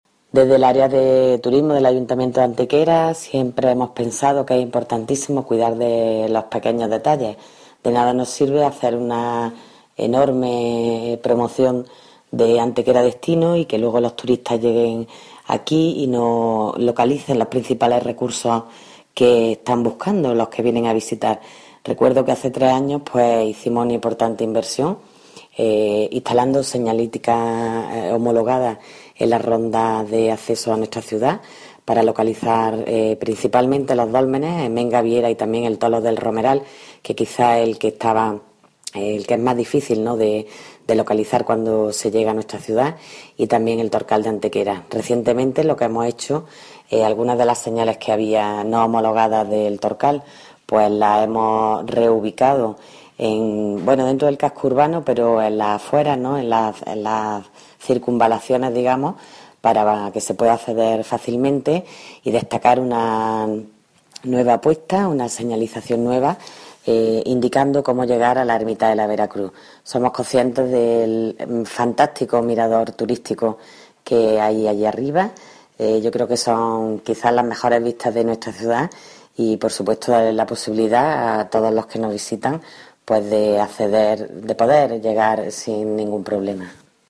Cortes de voz B. Jiménez 682.26 kb Formato: mp3